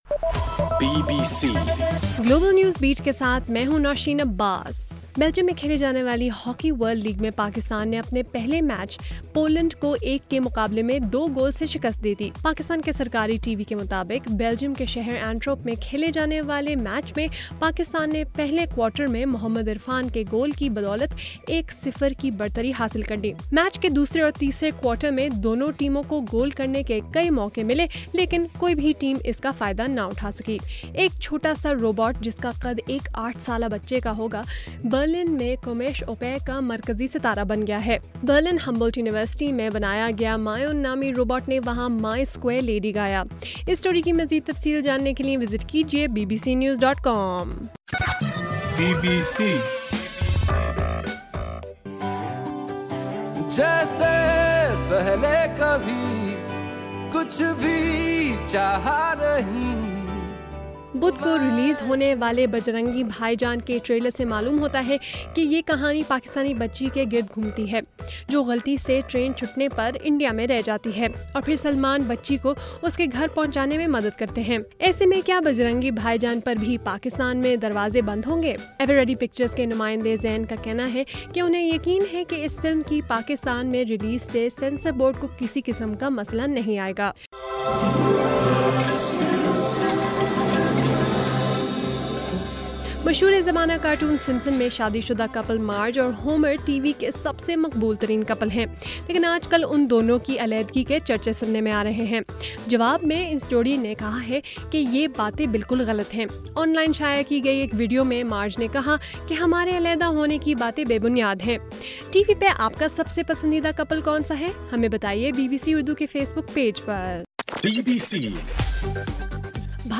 جون 21: رات 11 بجے کا گلوبل نیوز بیٹ بُلیٹن